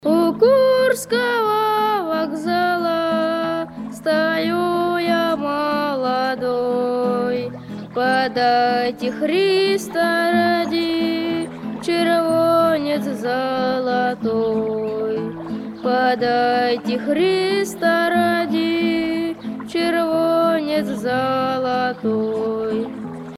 грустные , детский голос